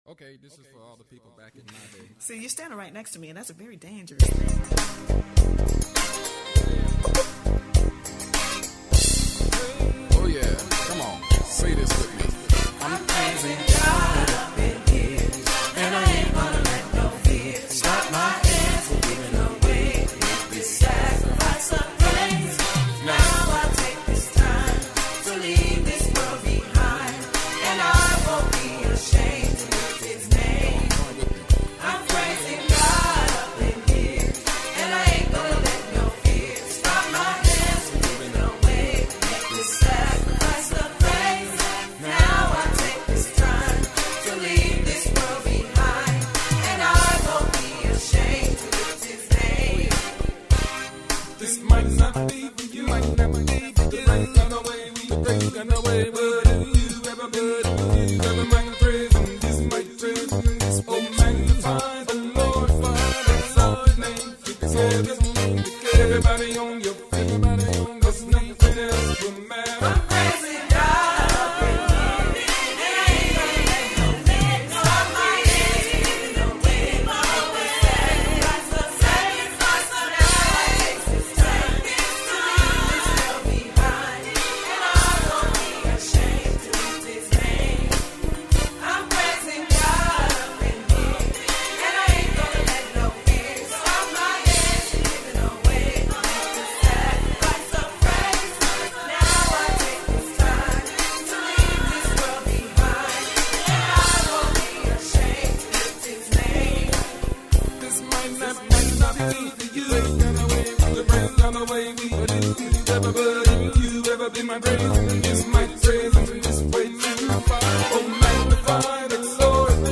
uniquely, simplistic style